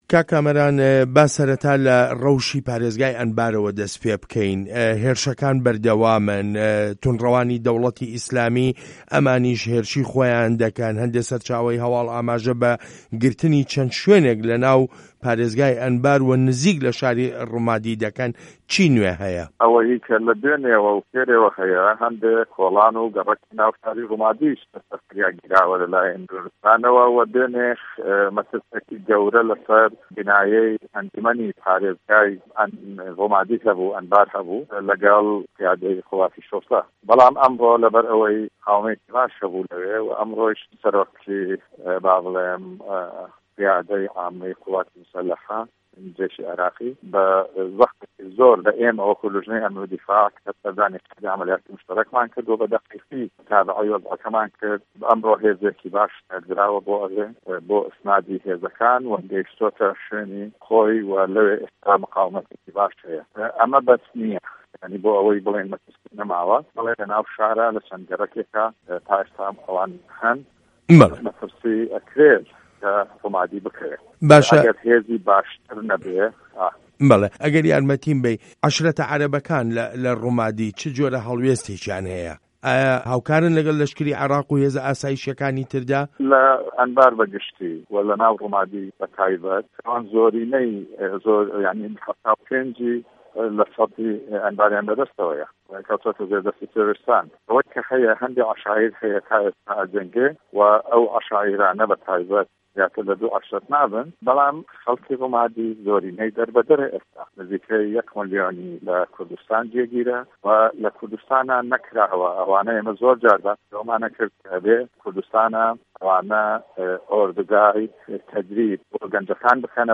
وتووێژ له‌گه‌ڵ کامه‌ران مه‌لاحه‌سه‌ن